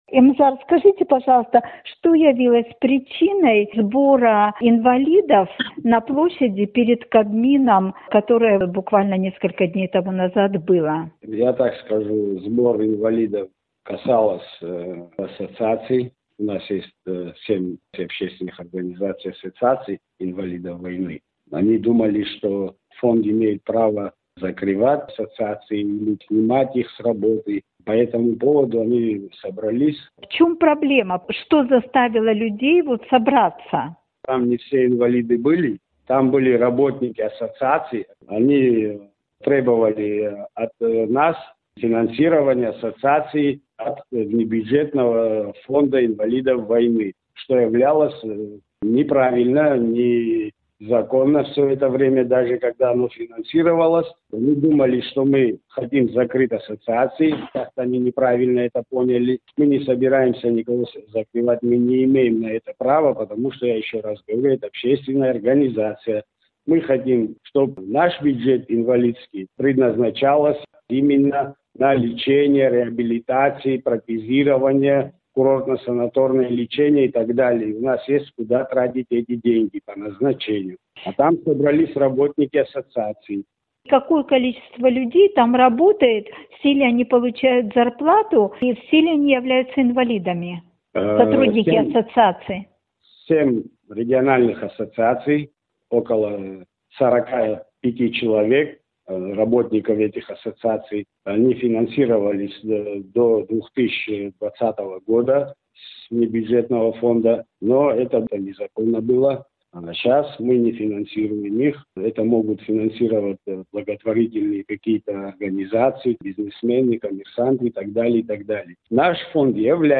Фонд инвалидов Отечественной войны народа Абхазии ответил на претензии общественных организаций инвалидов, которые прозвучали на встрече с премьер-министром в понедельник. Фонд отказывается их впредь финансировать, так как считает эту деятельность незаконной. Об это в интервью «Эху Кавказа»...